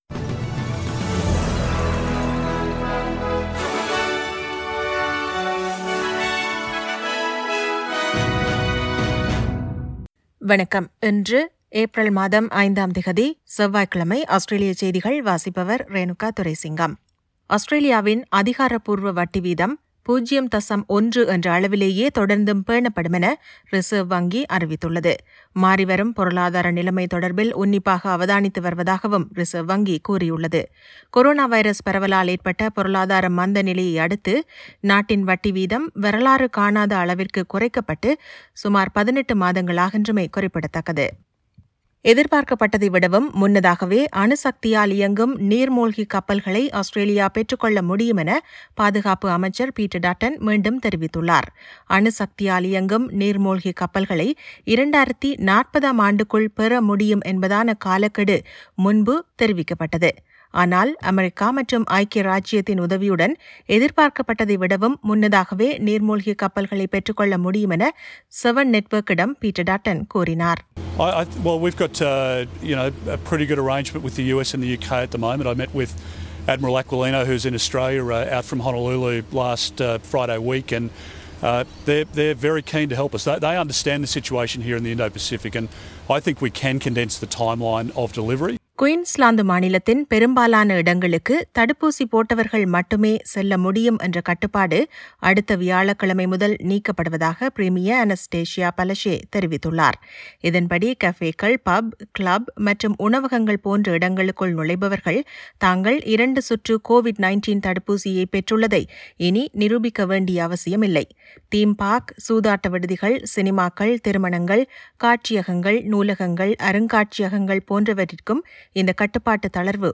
Australian news bulletin for Tuesday 05 April 2022.